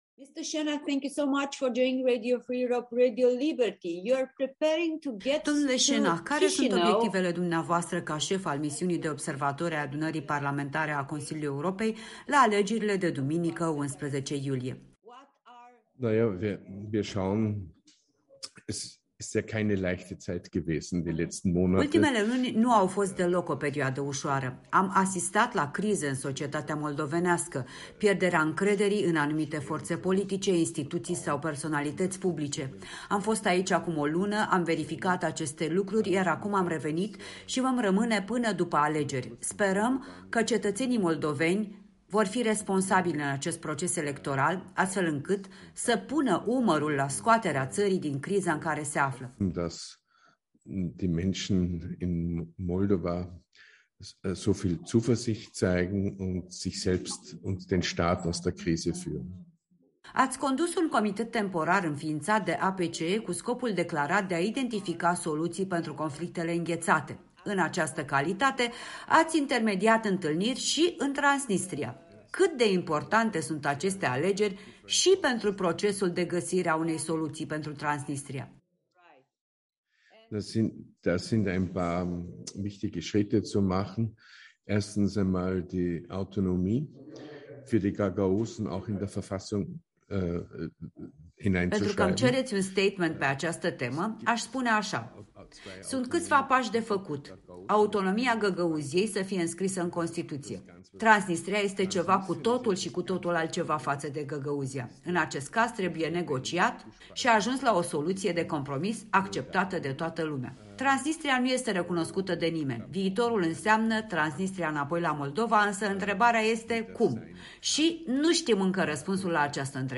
Interviu cu Stefan Schennach